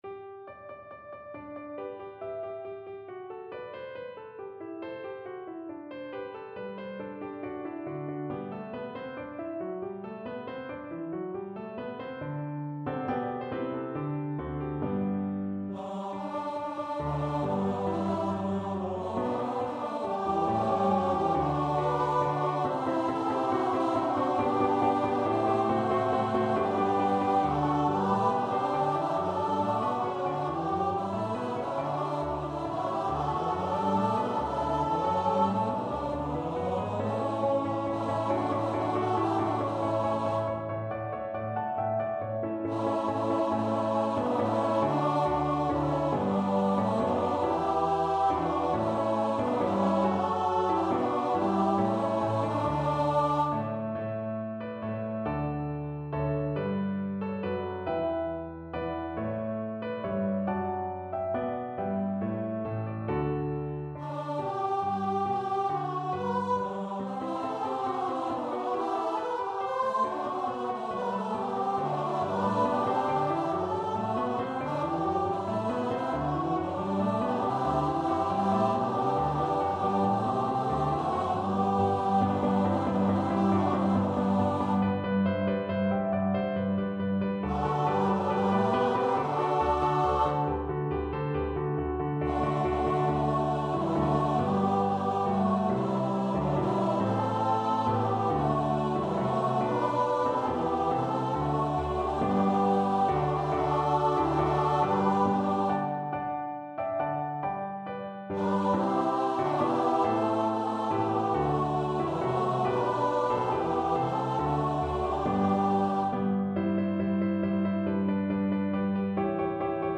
Free Sheet music for Choir (ATB)
Choir  (View more Intermediate Choir Music)
Classical (View more Classical Choir Music)